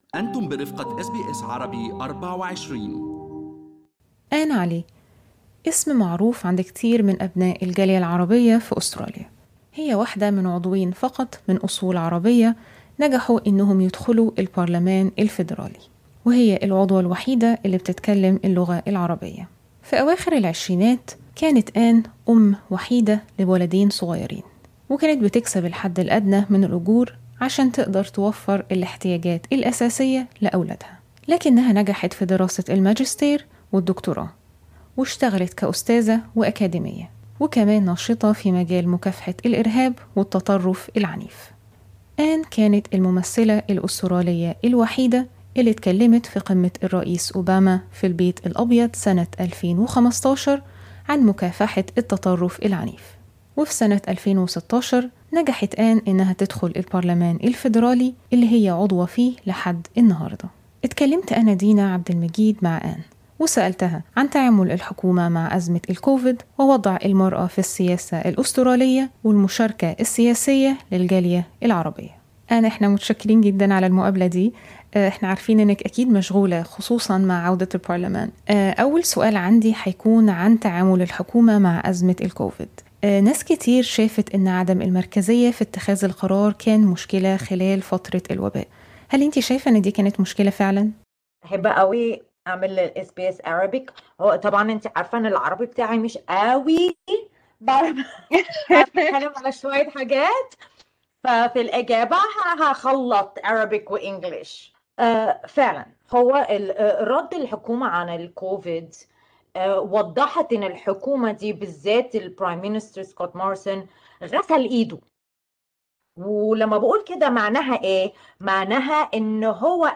interview_web_withtopandtail.mp3